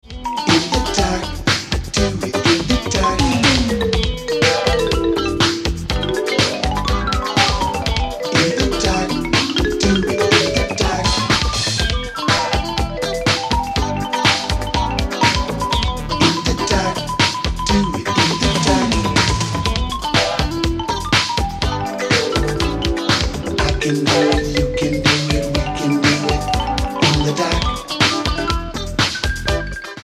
Disco monster